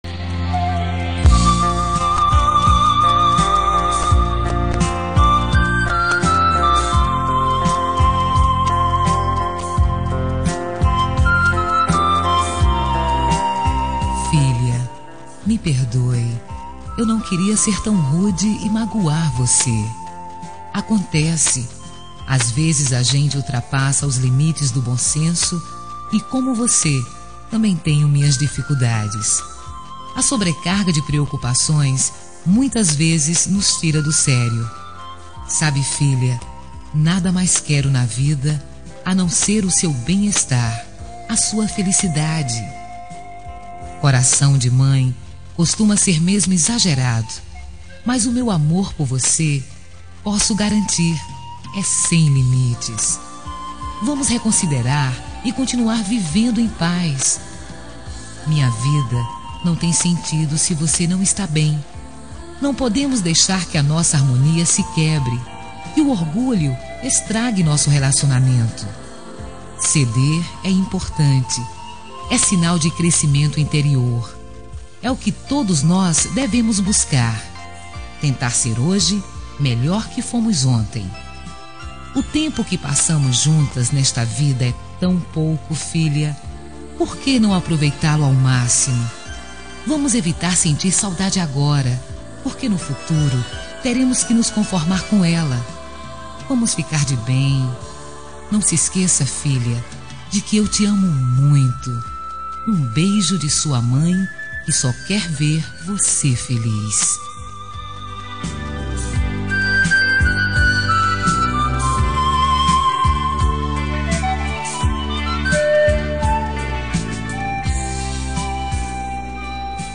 Reconciliação Familiar – Voz Feminina – Cód: 088728 – Filha